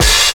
101 OP HAT 2.wav